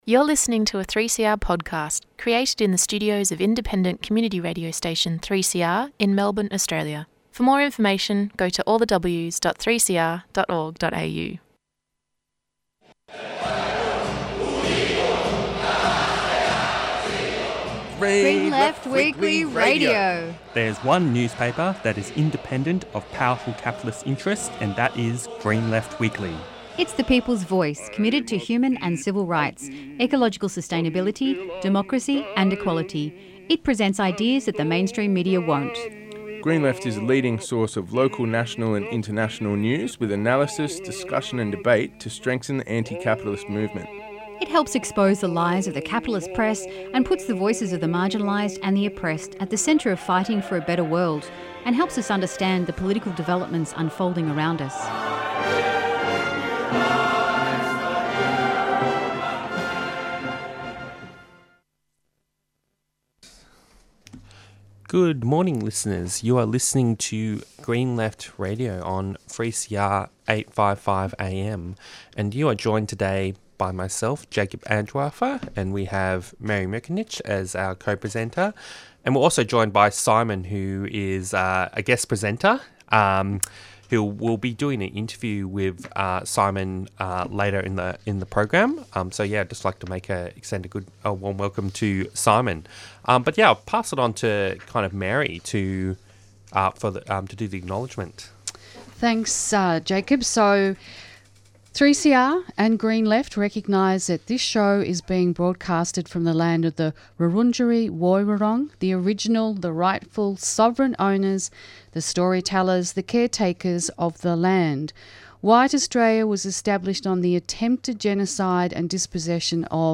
Newsreports Presenters discuss the Victorian teachers strike for school and teacher funding on March 24 and ABC workers taking strike action for pay, secure jobs . Interviews and Discussions